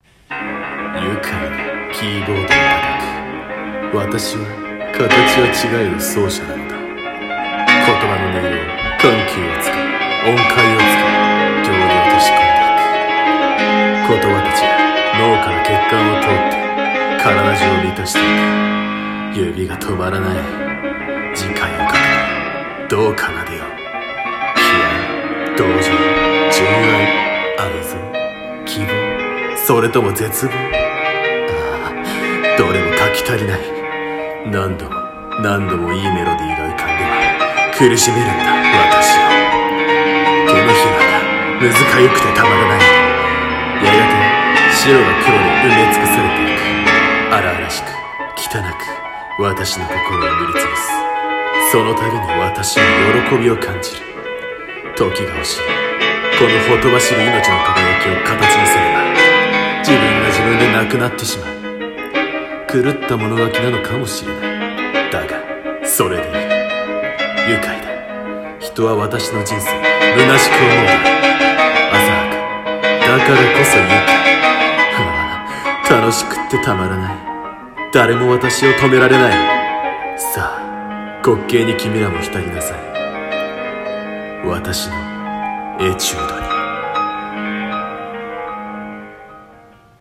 【一人声劇】狂奏者